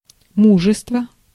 Ääntäminen
IPA : /ˈvæl.ə(ɹ)/